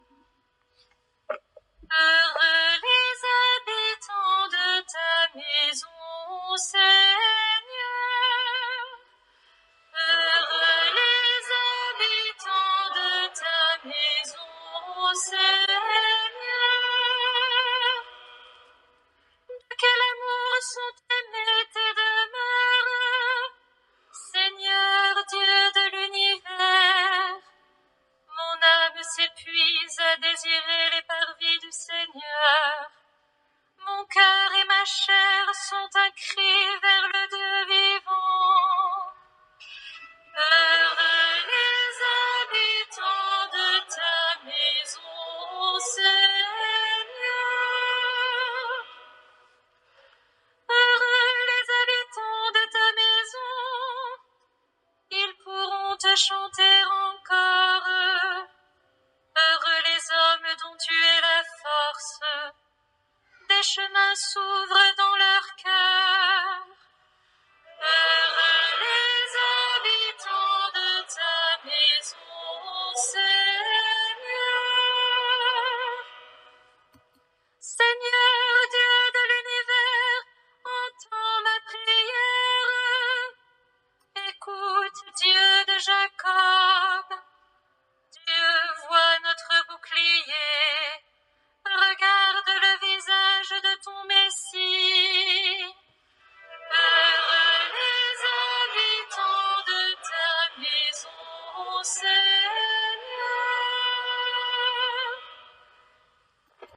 Messe solennelle dans la pro-cathédrale Saint-Etienne de Nevers.
Psalmodié